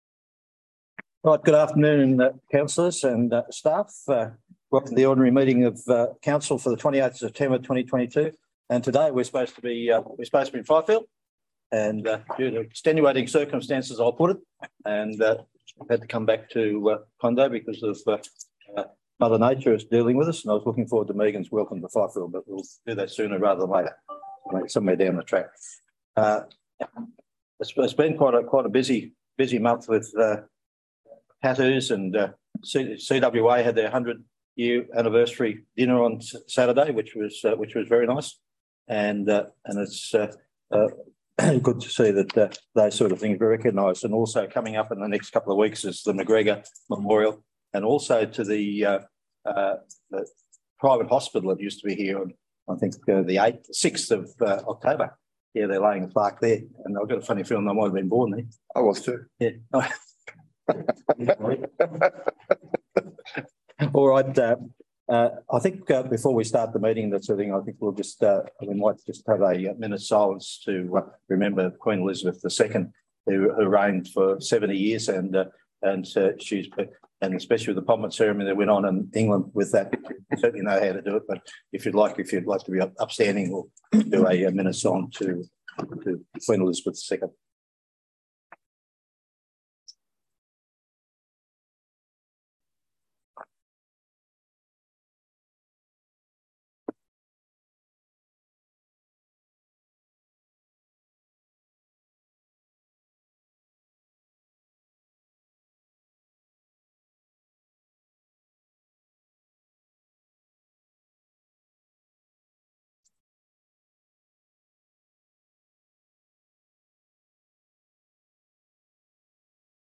The 28 September meeting will be held in the Council CHambers and is open to the public